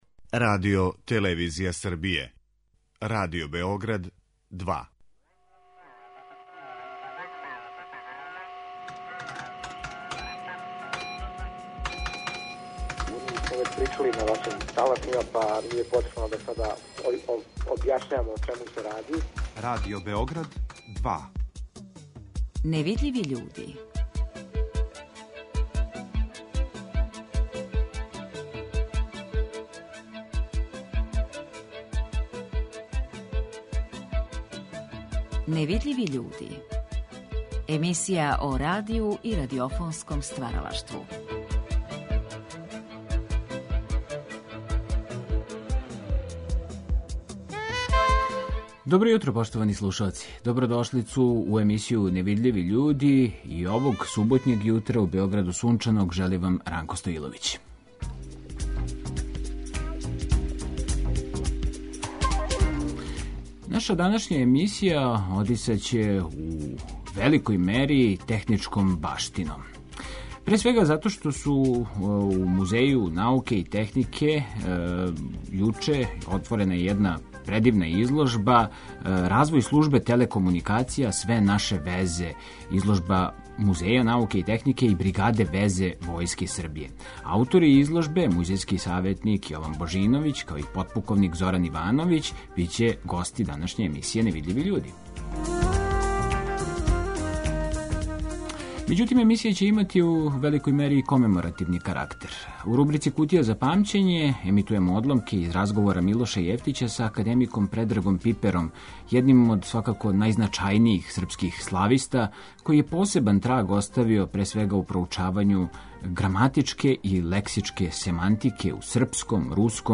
У знак сећања на композитора, певача, водитеља, забављача, Мињу Суботу, који је преминуо у 83. години, емитујемо разговор који смо са њим забележили за емисију „Невидљиви људи'.